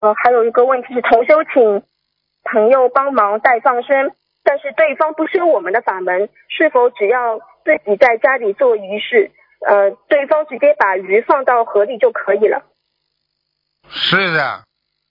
目录：☞ 2019年08月_剪辑电台节目录音_集锦